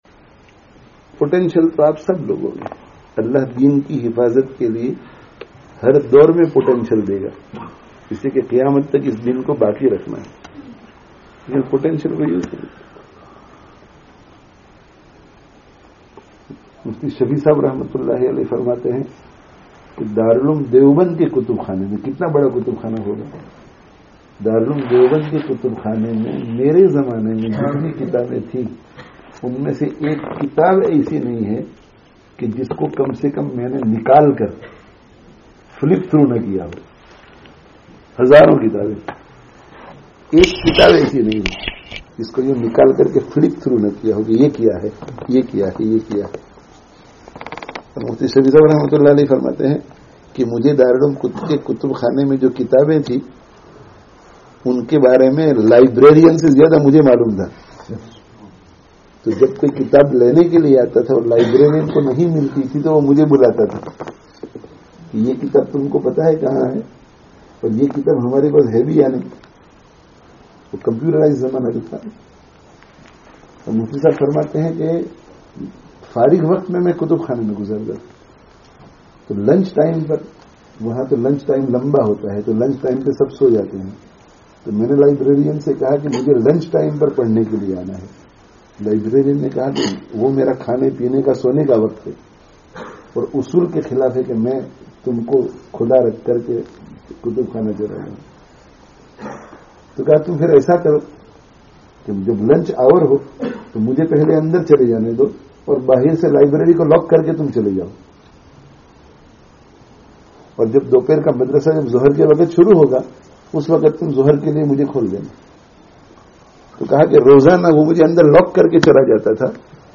[Informal Majlis] Apnī Salāhiyato(n) ko Dīn ke Kāmo(n) me(n) Lagāwo (12/12/19)